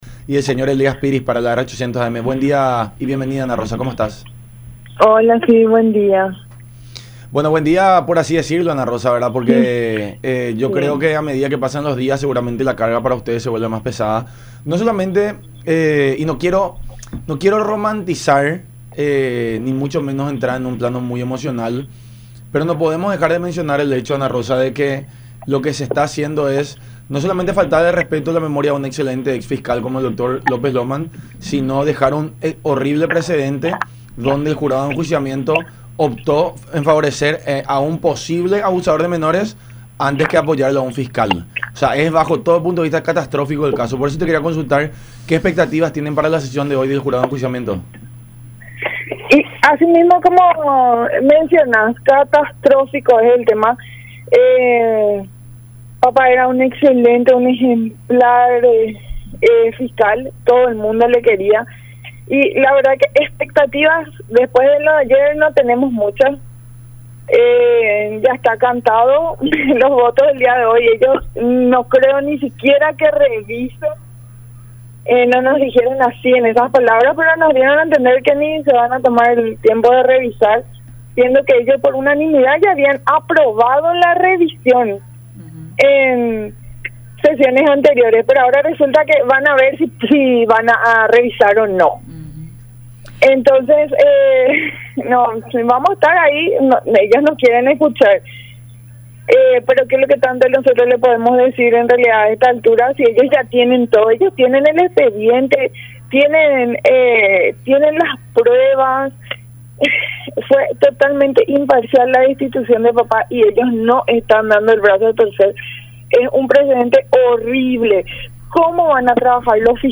en charla con La Unión Hace La Fuerza a través de Unión TV y radio La Unión.